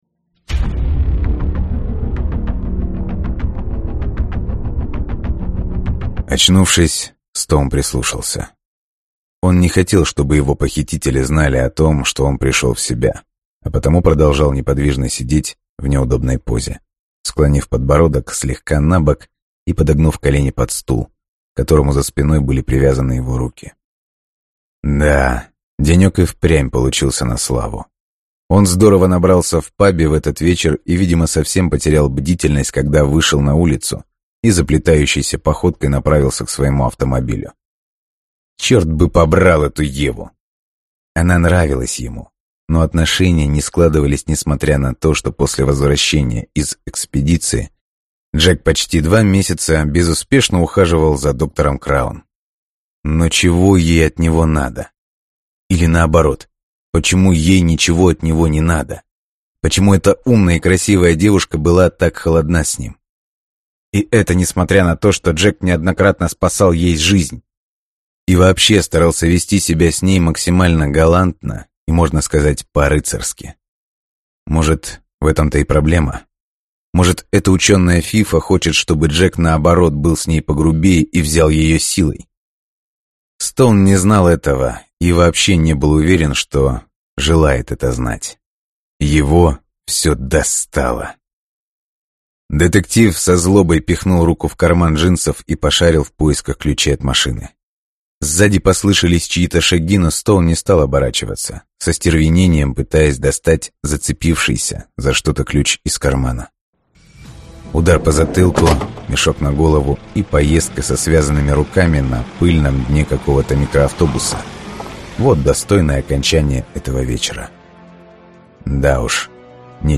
Аудиокнига Арсанты. Линии судьбы | Библиотека аудиокниг